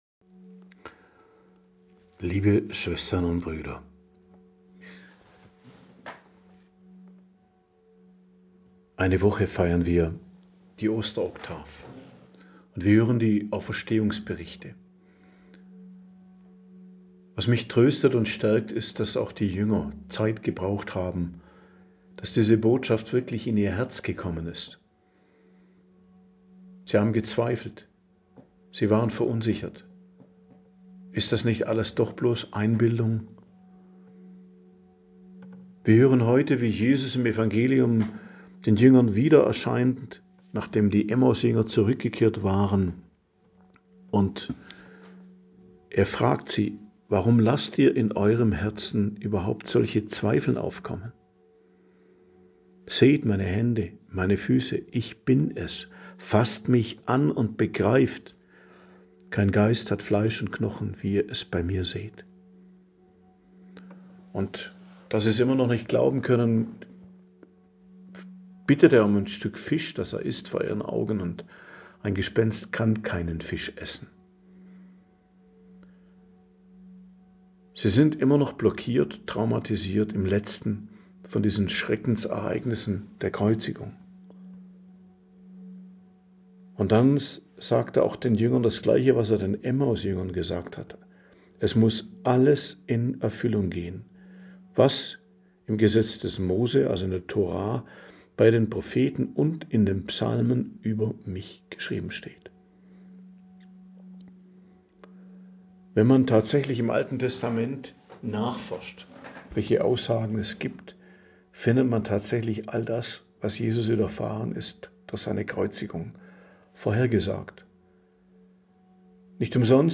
Predigt am Donnerstag der Osteroktav, 9.04.2026